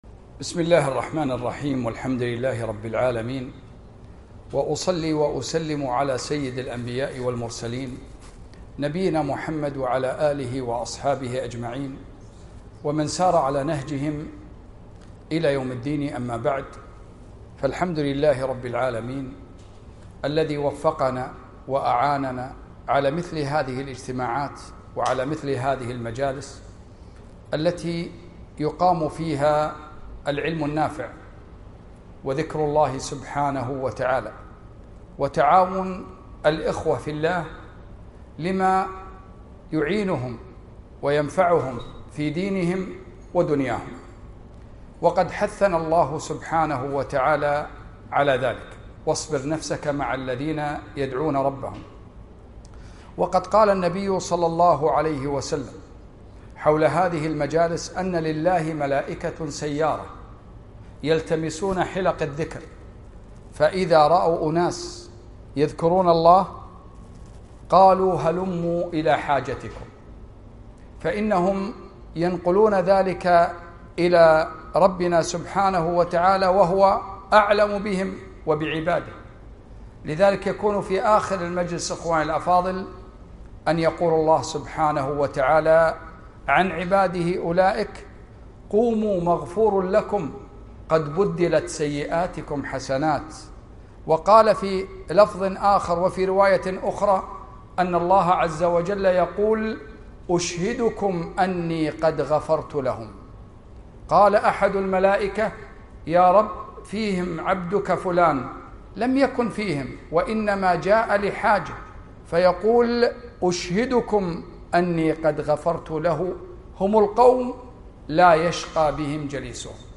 محاضرة - تاريخ الأنصار